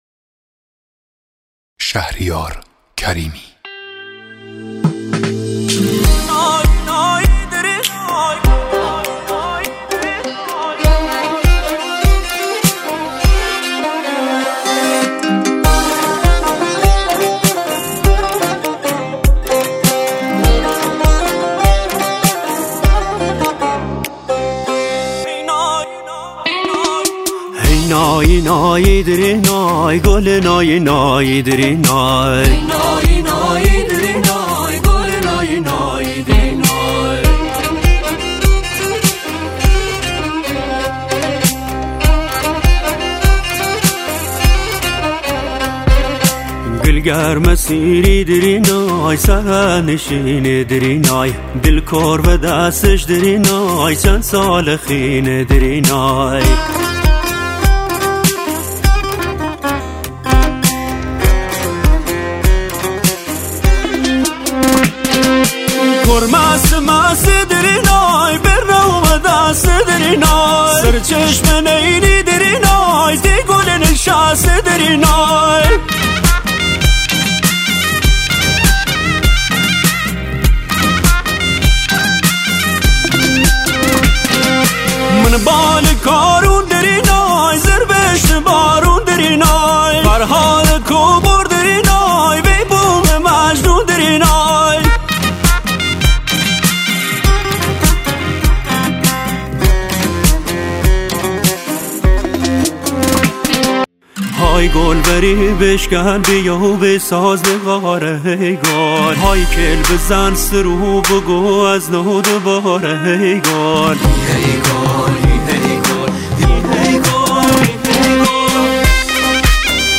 آهنگ شاد
یک آهنگ شاد لری
آهنگ فولکوریک لری